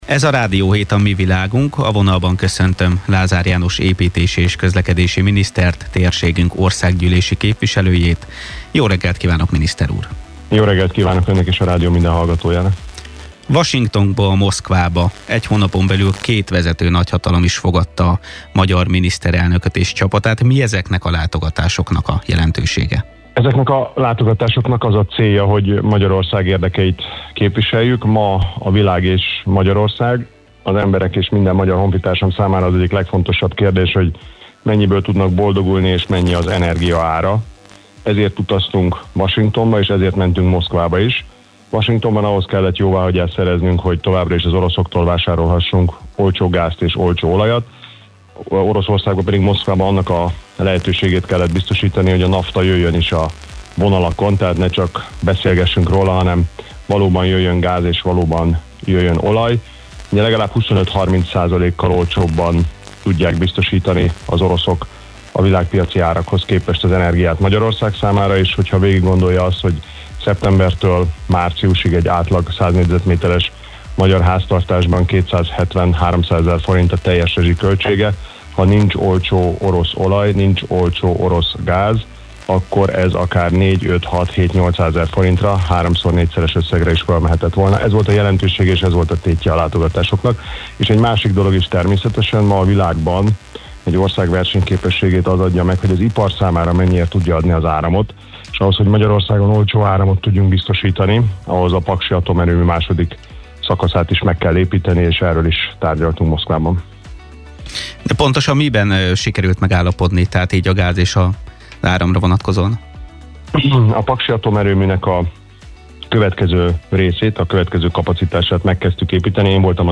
Promenad24 - Lázár János: az olcsó energia biztosítása volt a tétje a moszkvai utazásunknak (interjú)
int1201lazar_janos.mp3